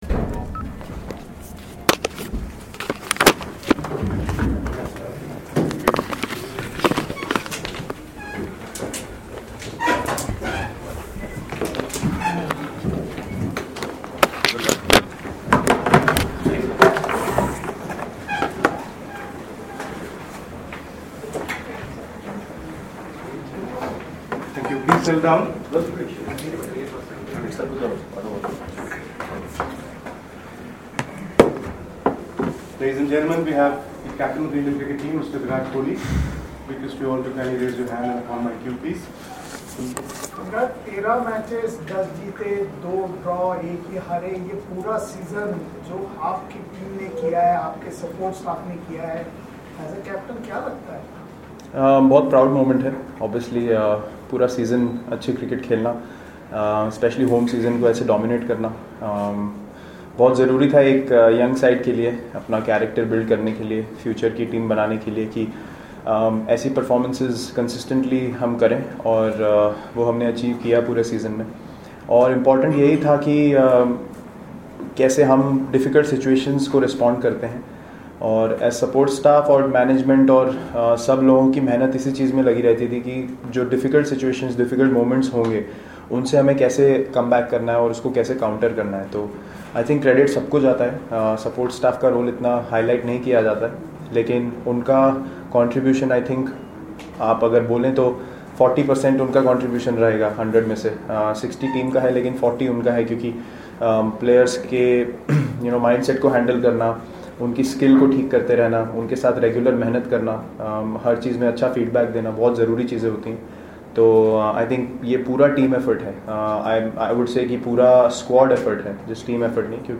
LISTEN: Virat Kohli speaks after Team India lifted the Border Gavaskar trophy